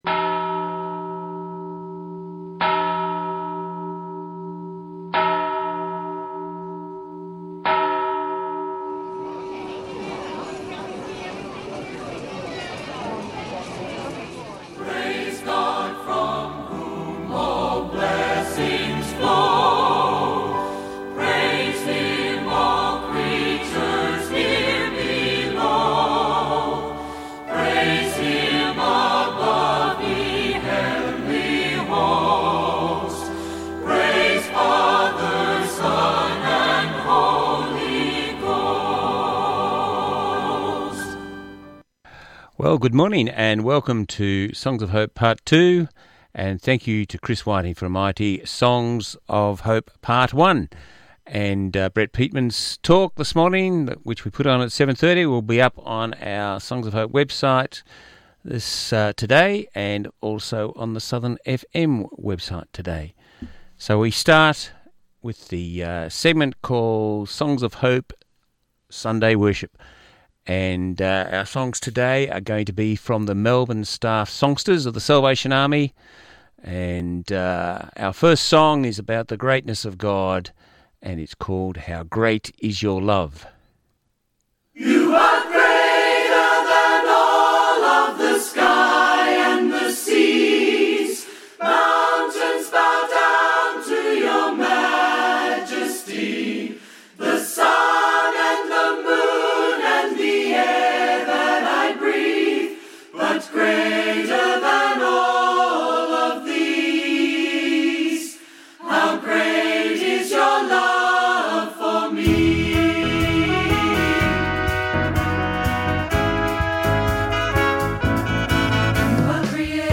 Listen again to Songs of Hope Sunday Worship, broadcast on 6Jan19.
It contains Christian songs, prayers, Bible reading, and Morning Devotions.